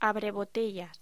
Locución: Abrebotellas
voz